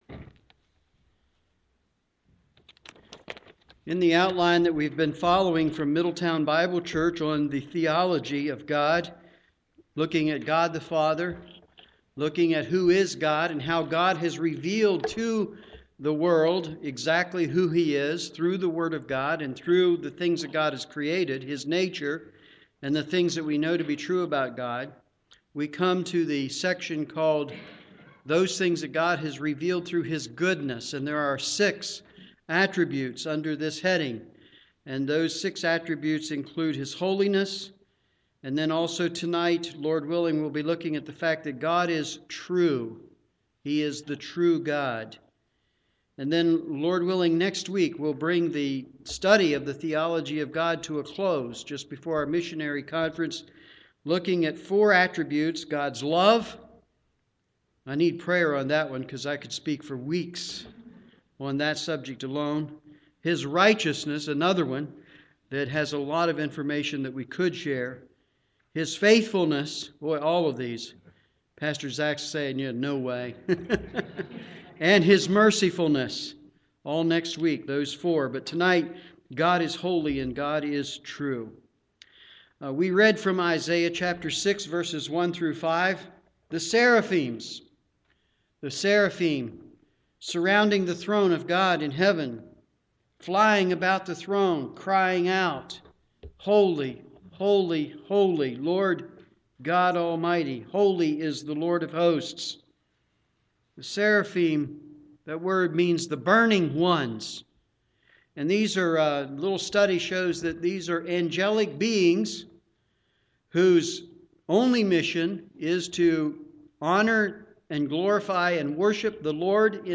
Evening Service
Sermon